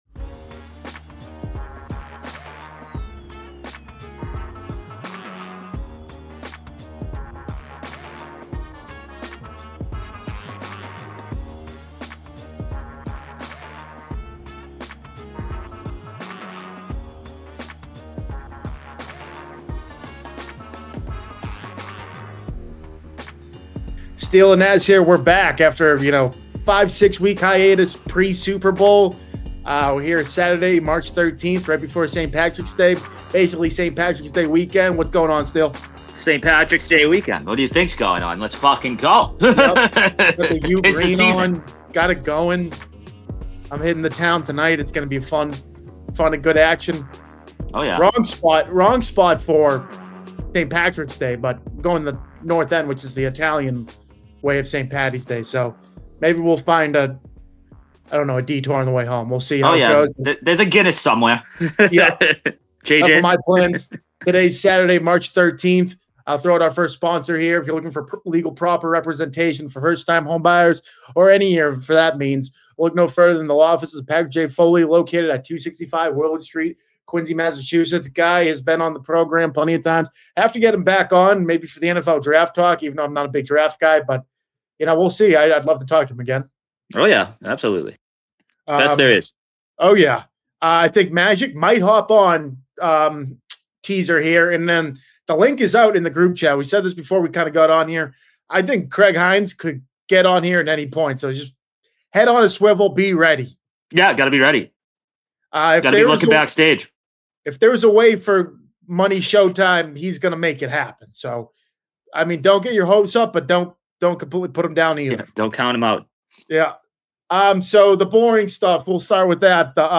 ran a no-guest REMOTE show